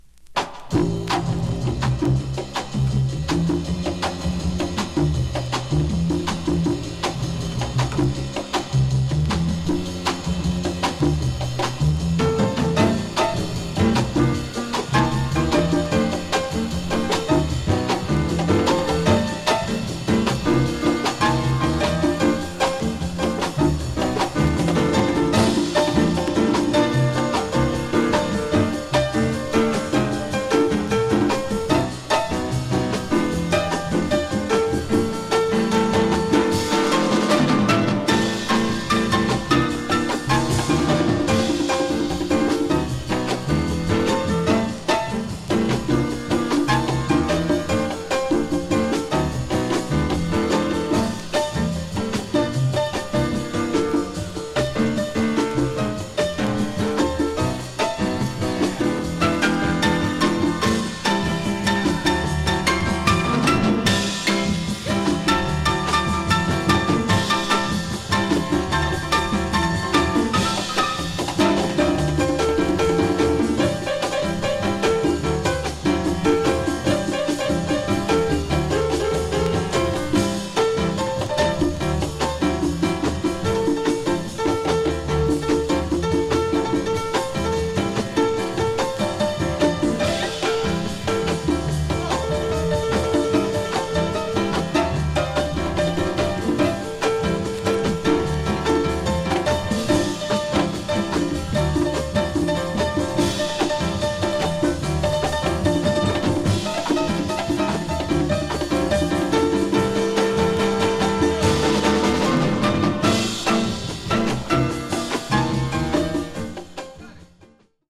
ドライヴ感のある